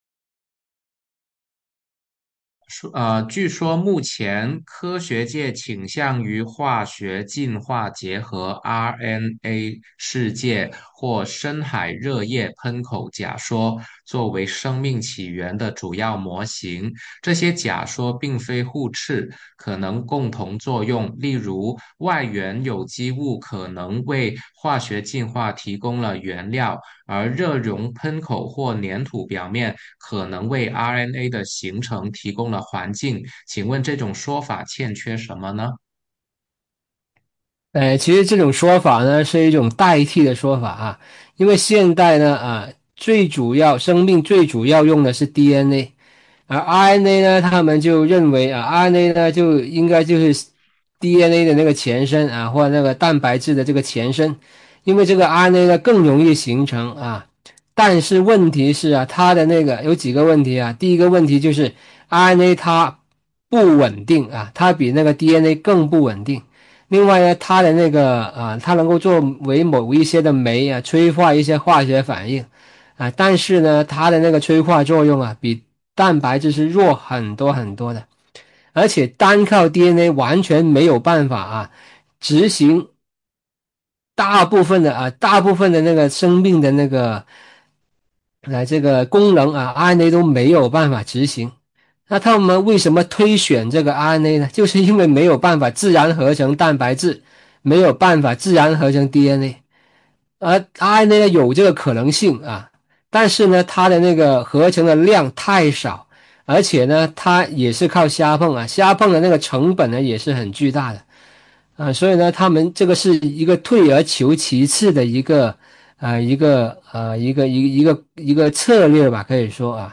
《生命的起源：创造还是进化》讲座直播回放
下载视频 下载PPT 点击下载问答环节音频 片名： 《生命的起源：创造还是进化》 片长： 63分钟 字幕： 简体中文 语言： 普通话 简介： 进化论常常宣传一个故事：通过“原始海洋”中的化学物质互相反应就能形成蛋白质，甚至生命——但实验证明，这个故事是完全违背科学的。